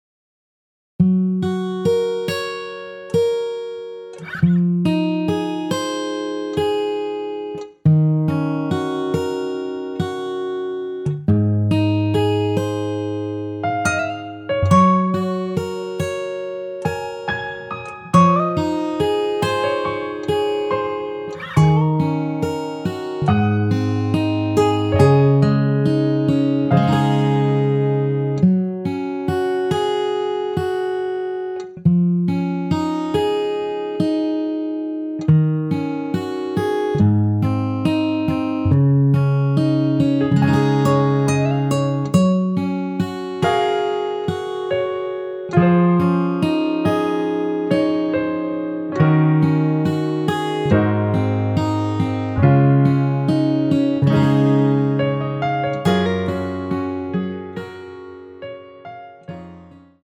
원키에서(+3)올린 MR입니다.
여성분이 부르실수 있는 키로 제작 하였습니다.(미리듣기 참조)
Db
앞부분30초, 뒷부분30초씩 편집해서 올려 드리고 있습니다.